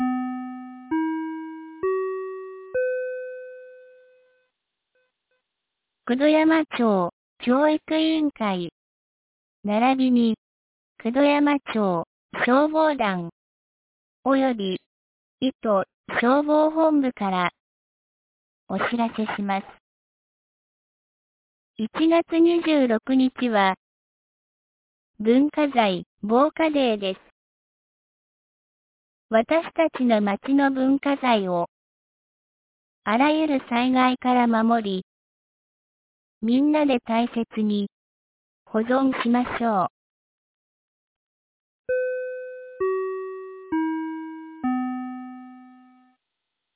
2025年01月20日 12時10分に、九度山町より全地区へ放送がありました。